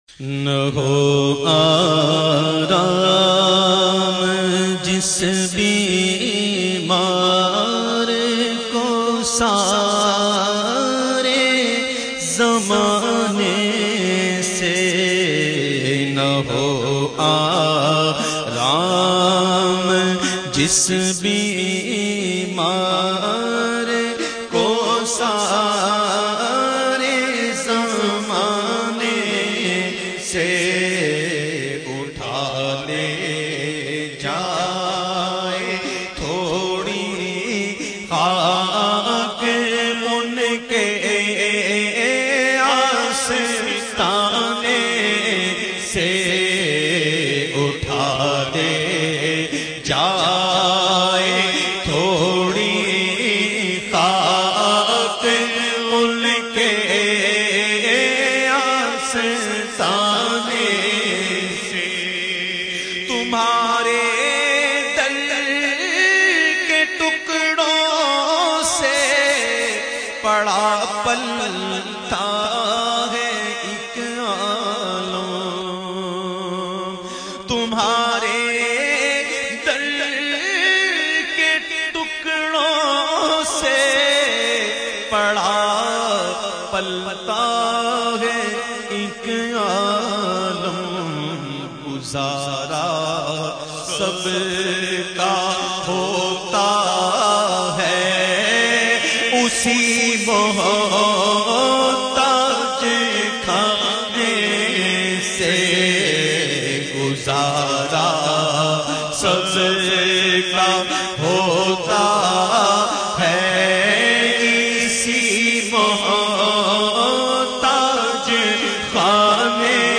The Naat Sharif Na Ho Aram Jis Bimar Ko recited by famous Naat Khawan of Pakistan Owaise Qadri